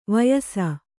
♪ vayasa